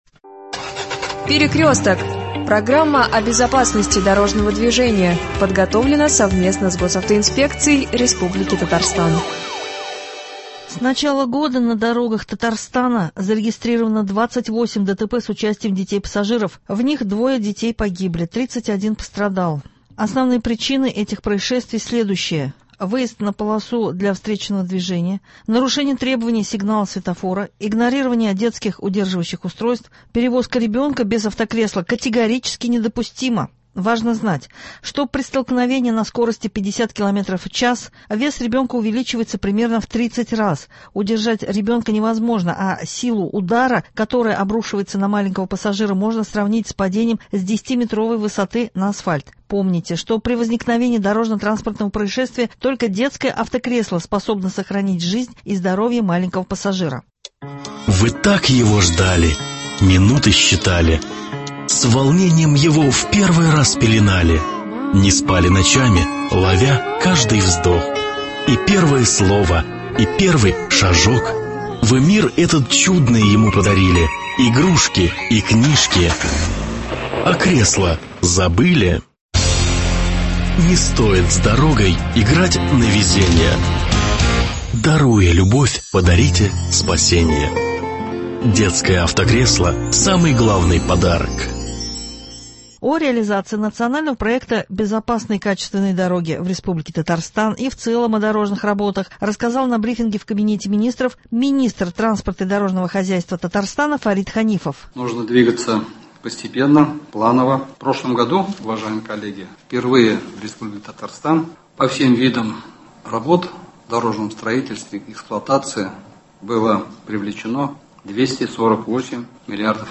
О реализации НП БКД в республике Татарстан и в целом о дорожных работах рассказал на брифинге в КМ р министра транспорта и дорожного хозяйства Татарстана Фарит Ханифов.